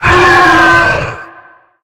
sounds / monsters / bloodsucker / die_2.ogg
die_2.ogg